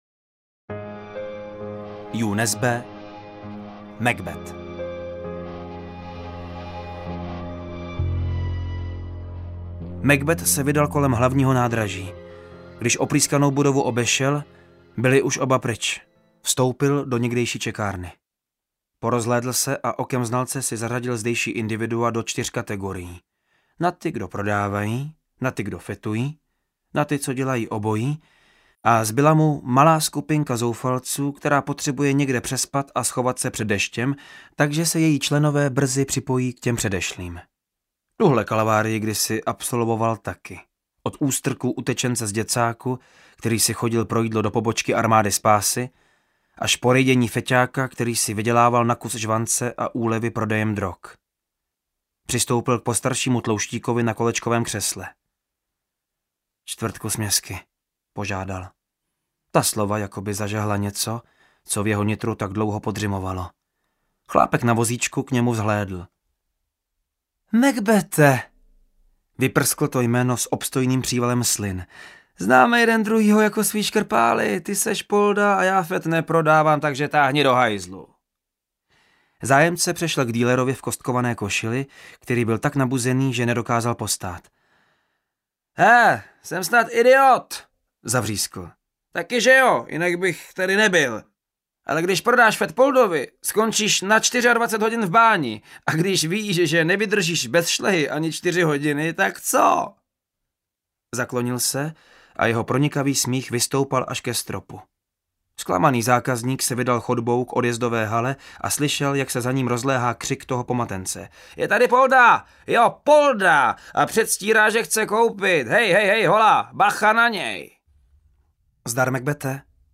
Macbeth audiokniha
Ukázka z knihy